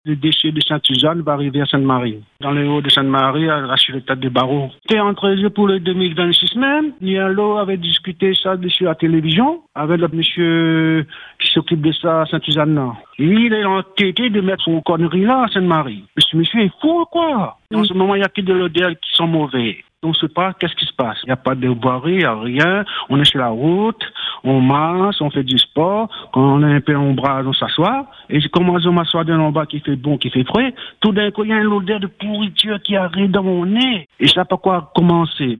Ce Saint-Marien que vous allez entendre exprime son inquiétude personnelle, sans détour.